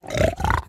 Minecraft Version Minecraft Version 1.21.5 Latest Release | Latest Snapshot 1.21.5 / assets / minecraft / sounds / mob / piglin_brute / idle1.ogg Compare With Compare With Latest Release | Latest Snapshot